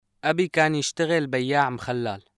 （ドラマ『バーブ・ル・ハーラ』第3部, 第2話より）